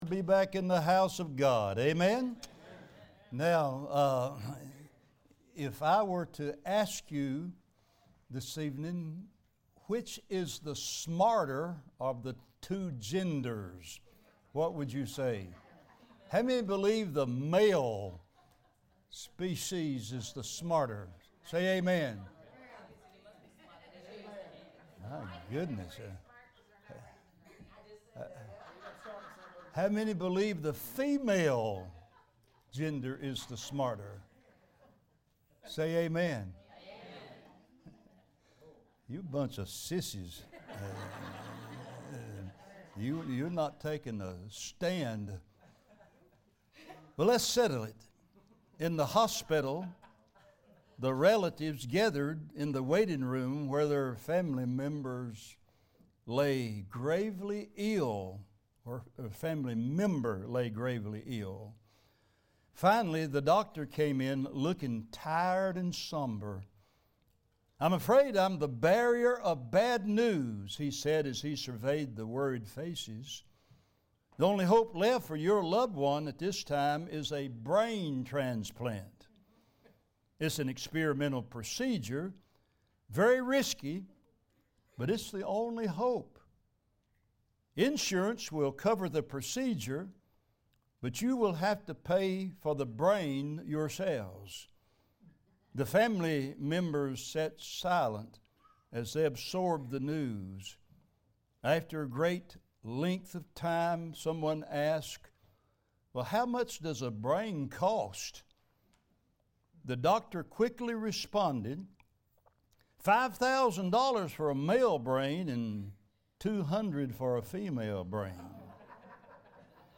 Passage: 1 Corinthians 15 Service Type: Sunday Evening Next Sermon